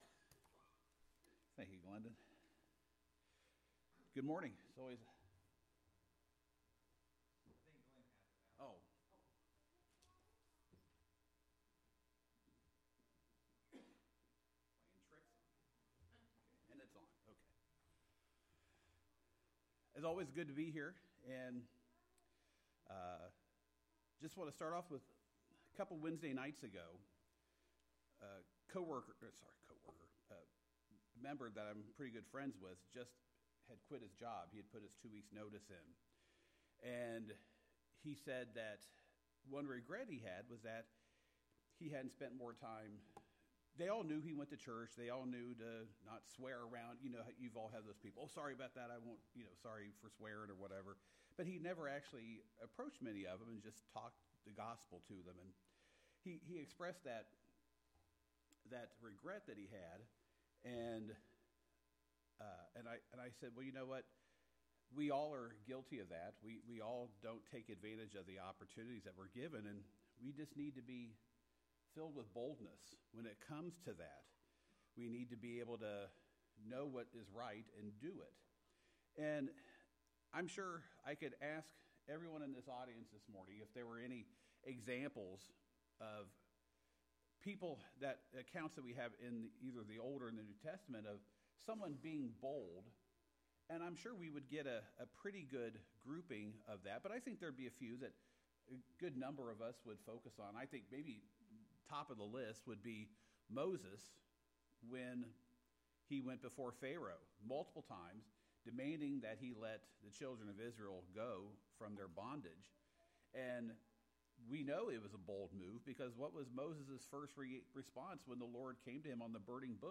The sermon goal is to encourage Christians to develop and demonstrate boldness rooted in faith in God rather than in personal courage or favorable circumstances.